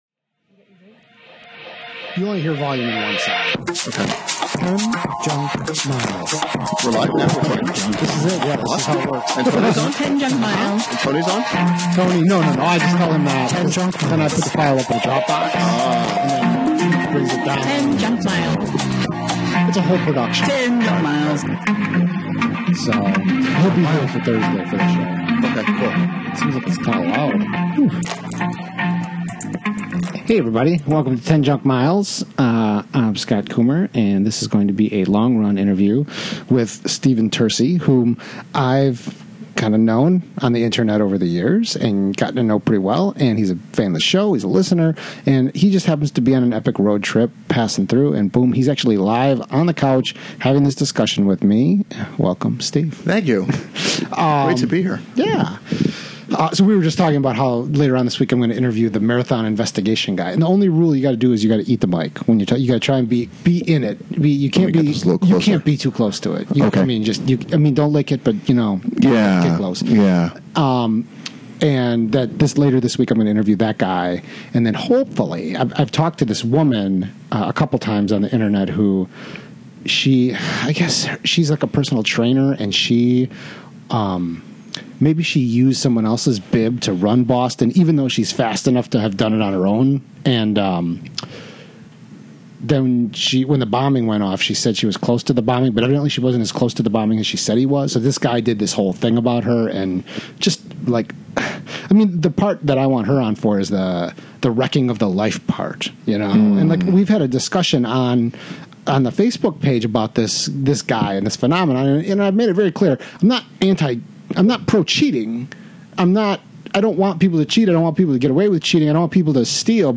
Podcast: Shortly after my sub-24 hour performance, I was interviewed on DFL Ultrarunning in April 2015 (links directly to audio file; my interview starts at 1hr 49mins.)